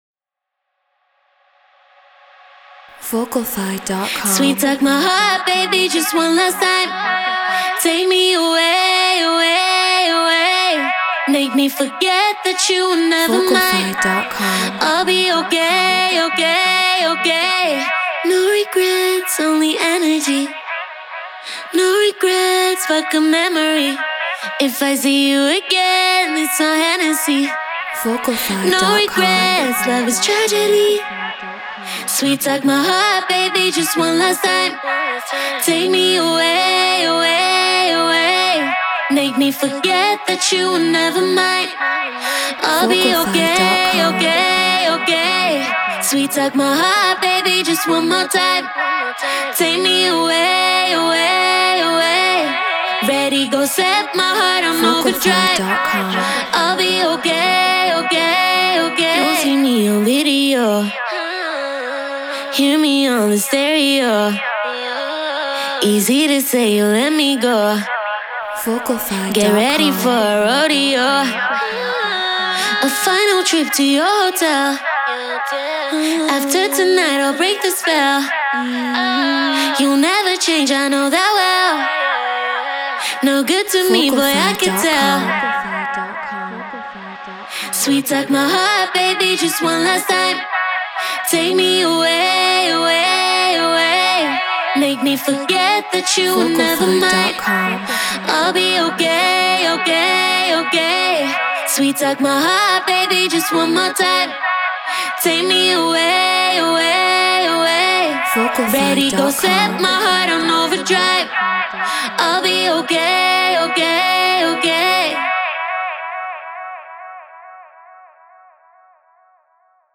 UKG 138 BPM C#min
Shure SM7B Focusrite Scarlett Ableton Live Treated Room